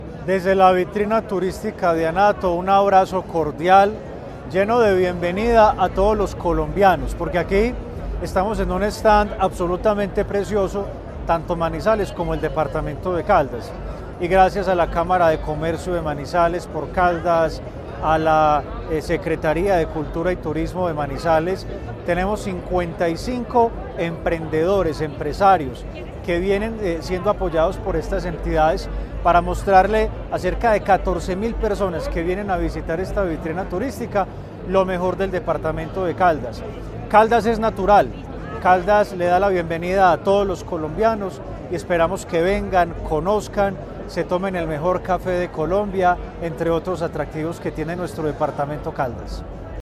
Gobernador_de_Caldas_Anato.mp3